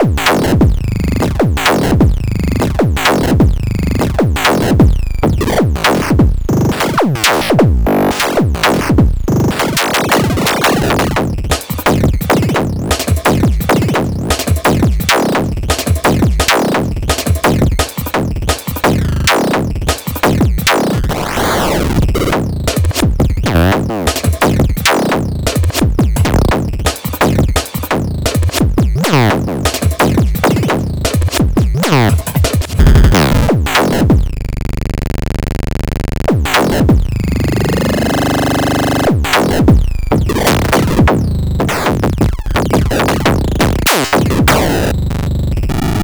At a glance, I thought might be boring, but after playing with it a bit, I’m loving the percussive chaos it can generate.
Here’s a snippet of my first dance with the Wavsynth:
(some breakbeat slices snuck in there too, but other than that just Wavsynth)